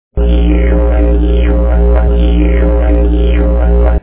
6vocal.wav